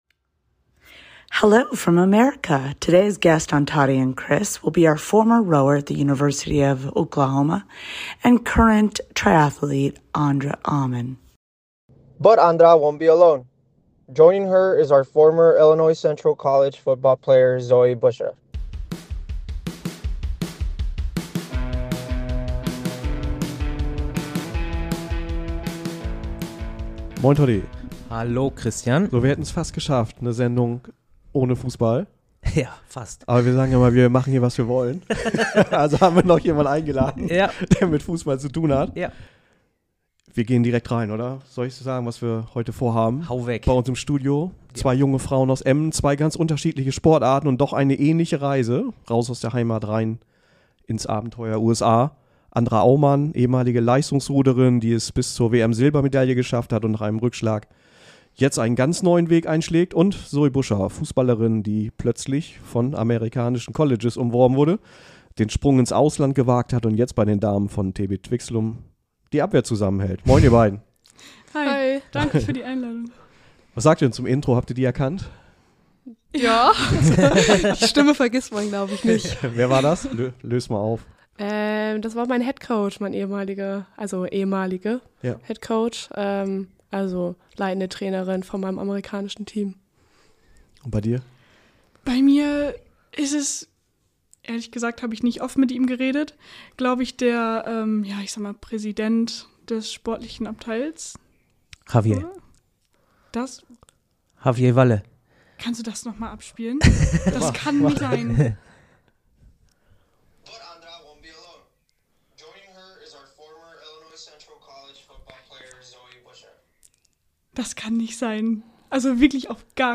Ein Gespräch über Abschiede und Neuanfänge, Eltern und Freunde, Fast Food und Schwarzbrot, Ehrgeiz und Erkenntnisse.